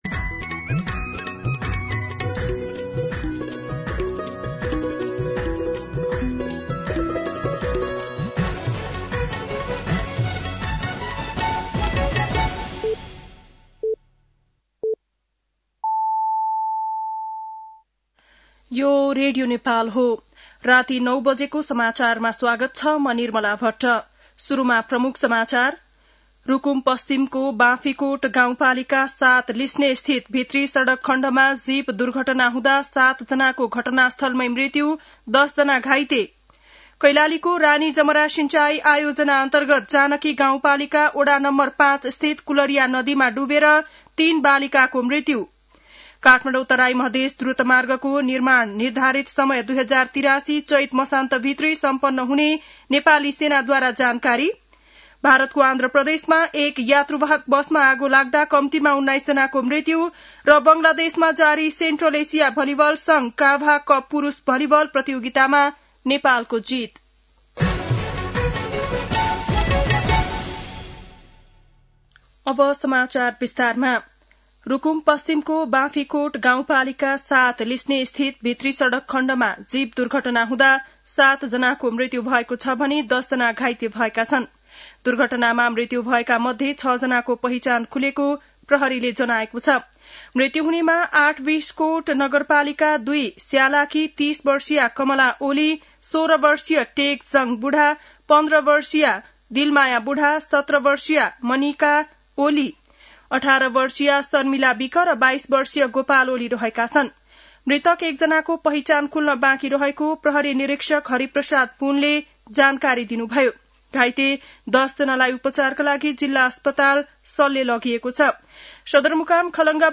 बेलुकी ९ बजेको नेपाली समाचार : ७ कार्तिक , २०८२